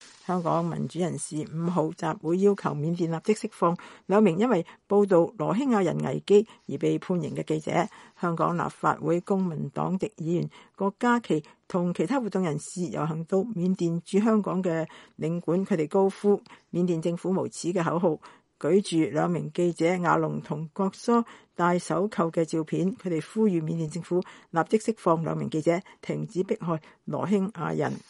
香港立法會公民黨籍議員郭家麒與其他活動人士遊行到緬甸駐香港領館，他們高呼‘緬甸政府無恥’的口號，舉著兩名記者瓦龍和覺梭帶手銬的照片。